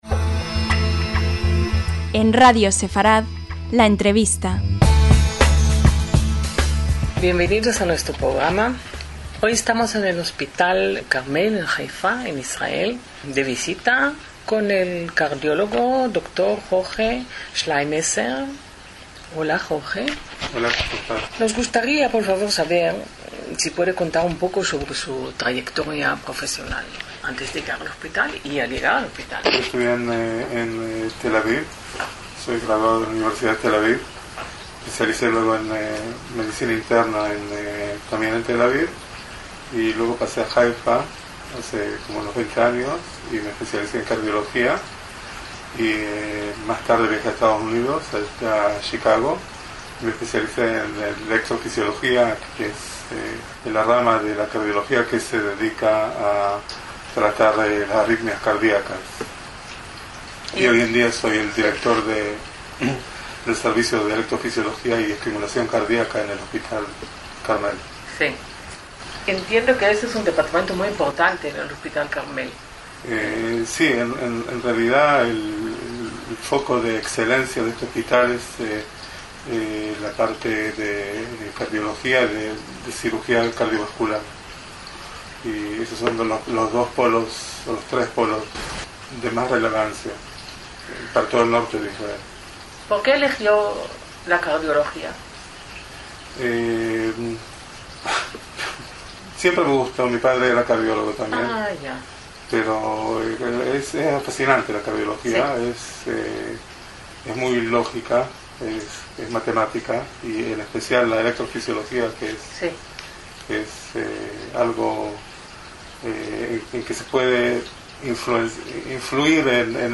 LA ENTREVISTA - El innovador marcapasos implantado en el hospital Carmel en noviembre de 2019 es mucho más pequeño que uno regular: tiene el tamaño de una moneda y no requiere la inserción de electrodos a través de una vena en el corazón.